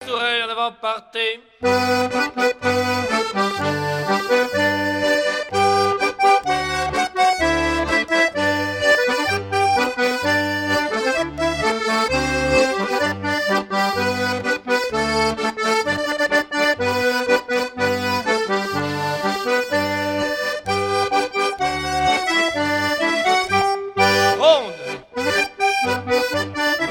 danse : quadrille : pastourelle
Pièce musicale éditée